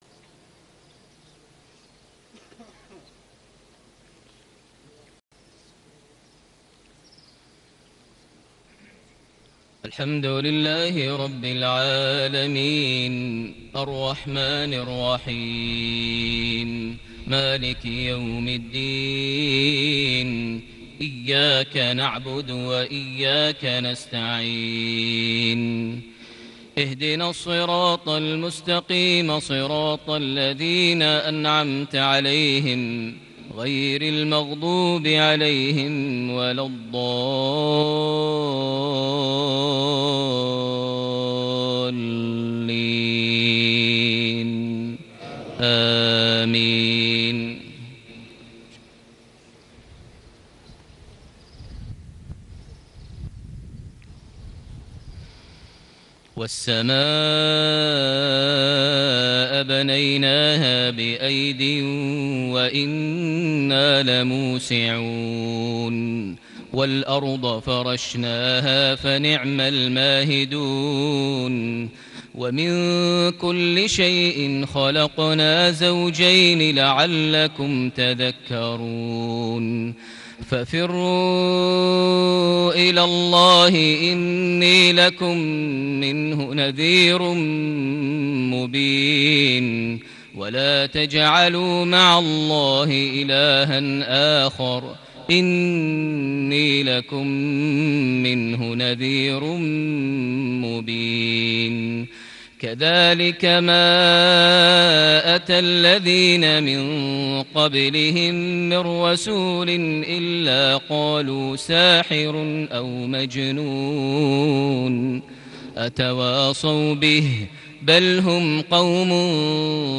صلاة المغرب ٢٨ ربيع الأول ١٤٣٨هـ خواتيم سورة الذاريات > 1438 هـ > الفروض - تلاوات ماهر المعيقلي